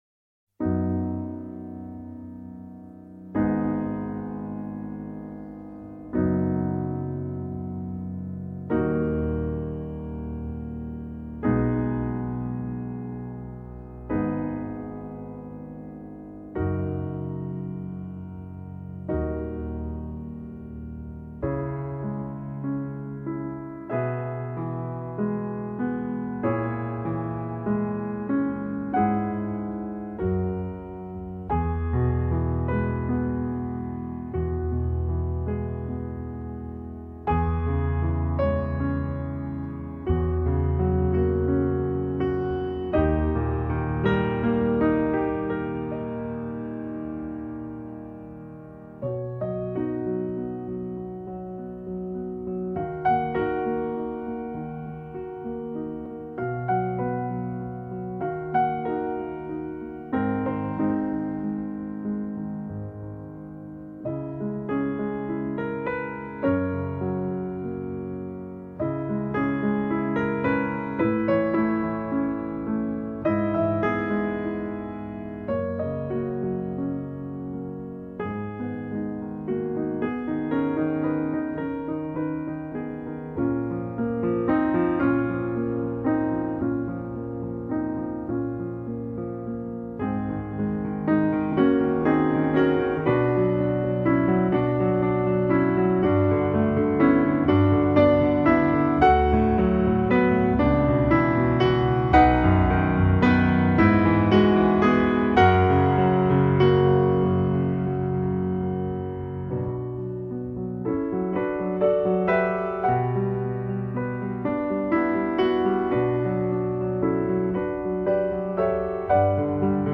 arrangés pour piano solo